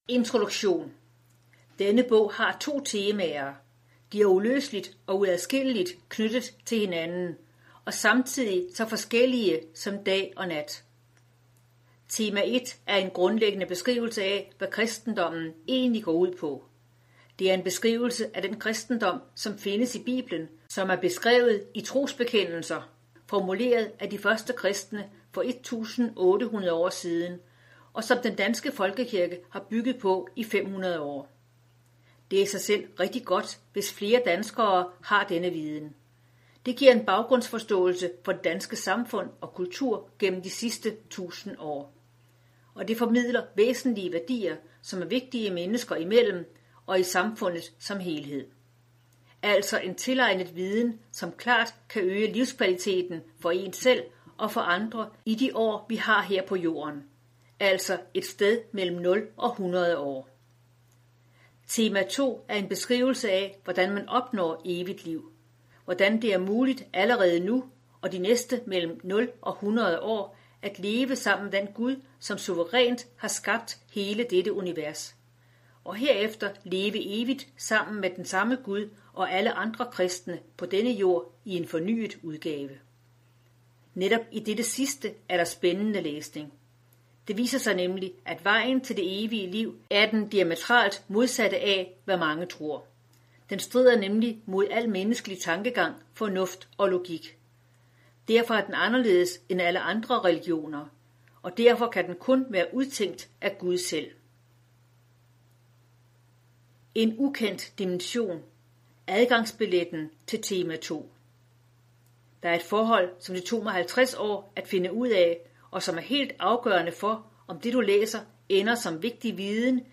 Hør et uddrag af Spot on Spot on Om Gud, os og verden – og vejen til den nye jord Format MP3 Forfatter Kim Hjermind Bog Lydbog 49,95 kr.